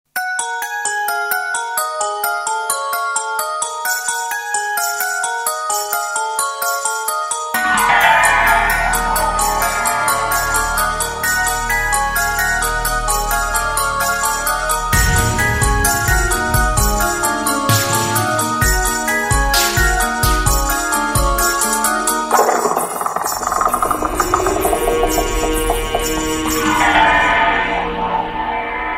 Category: Ringtones